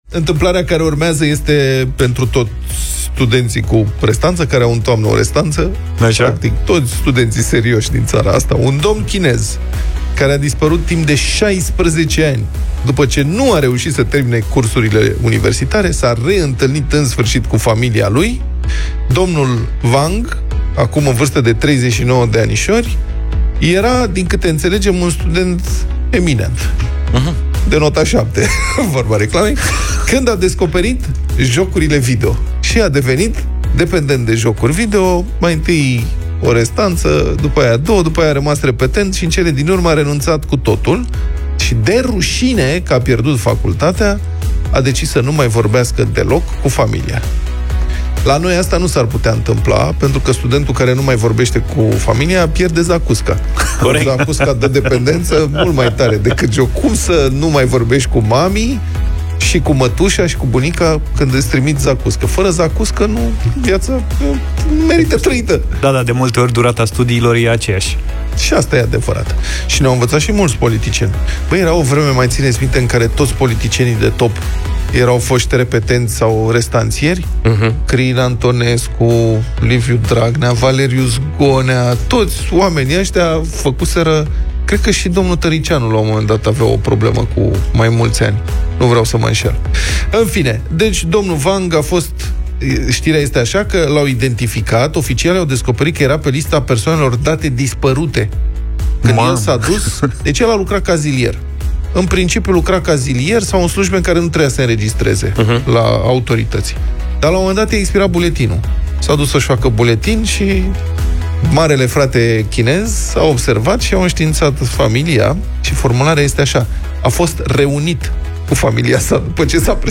au vorbit despre acest subiect în Deșteptarea.